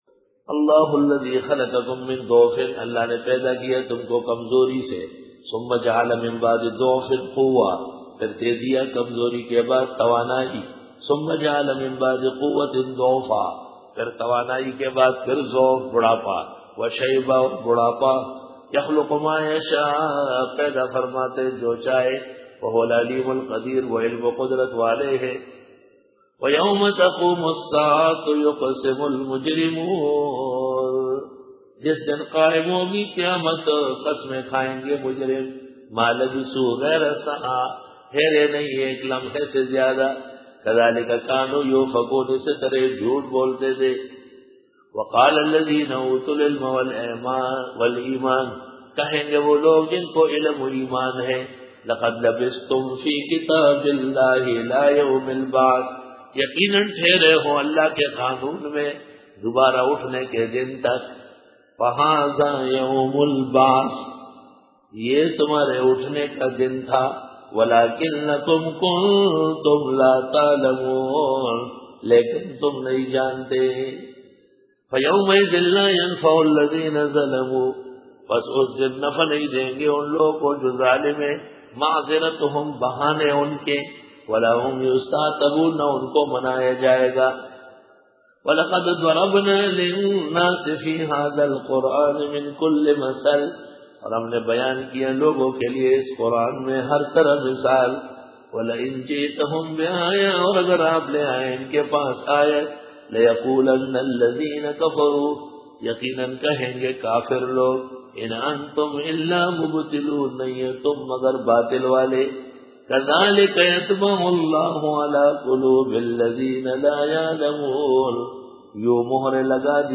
سورۃ الروم رکوع-06 Bayan